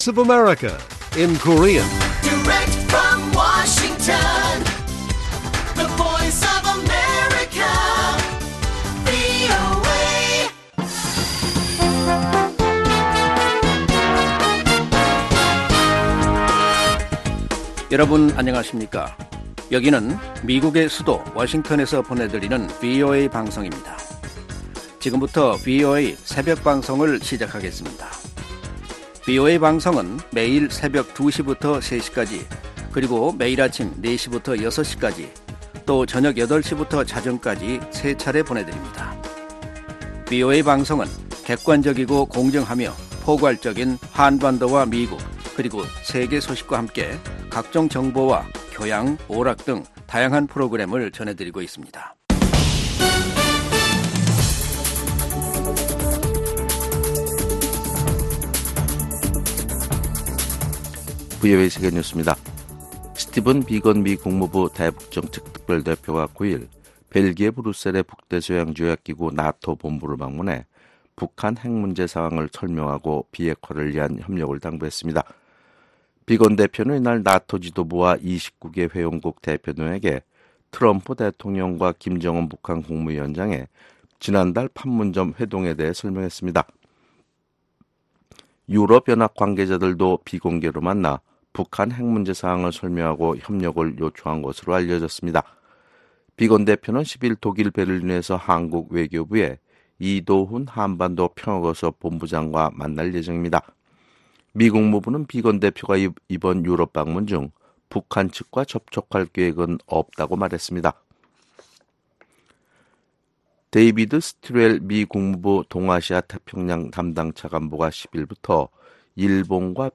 VOA 한국어 '출발 뉴스 쇼', 2019년 7월 11일 방송입니다. 미국 국무부는 북한 핵 동결은 비핵화 과정 초기에 이뤄져야 하며 결코 최종 목표가 될 수 없다고 밝혔습니다. 미국과 한국, 일본 등이 참가하는 연례 대량살상무기 확산방지 구상 PSI 훈련이 한국 부산 앞바다에서 시작됐습니다.